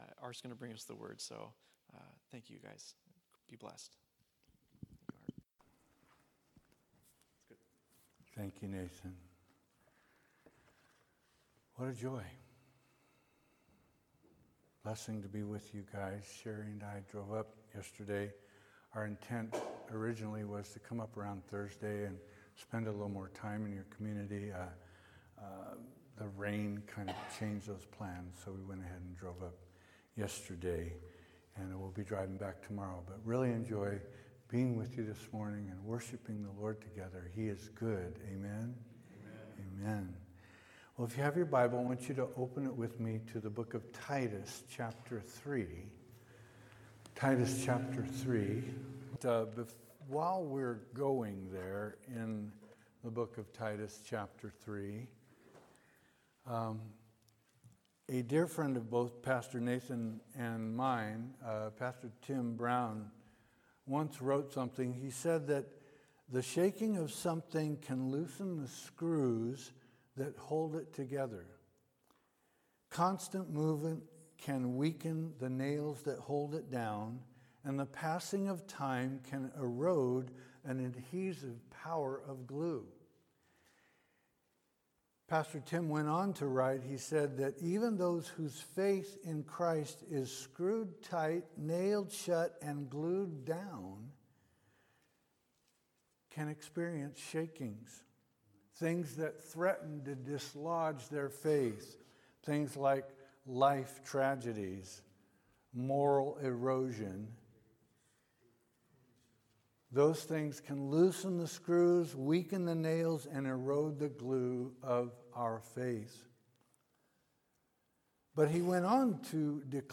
Sermons - Calvary Chapel Eureka